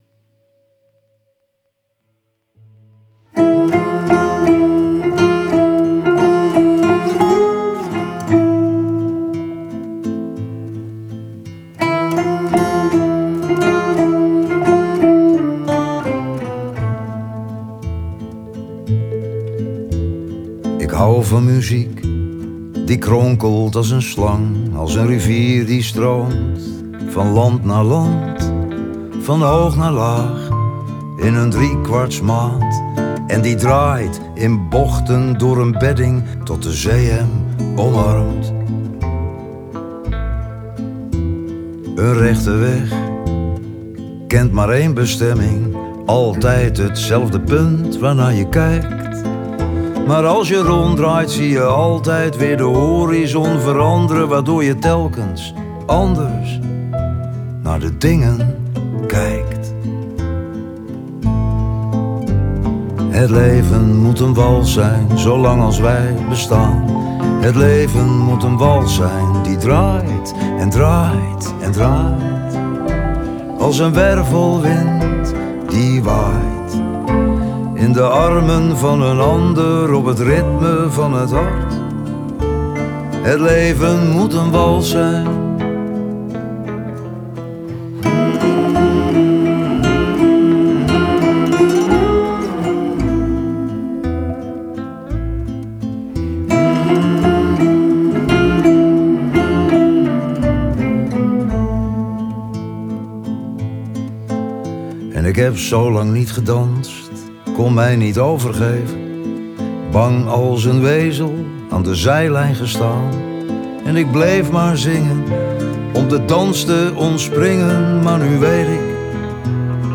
Lied